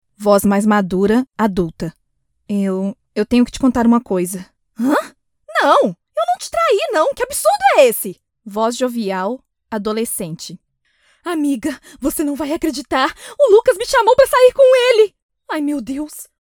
Locutoras de doblaje de Brasil